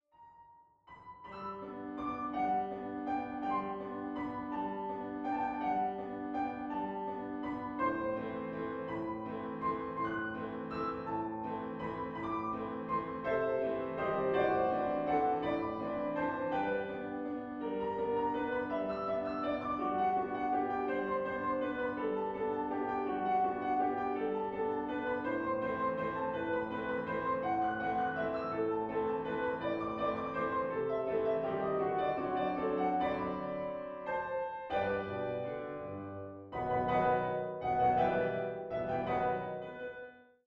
Klavier-Sound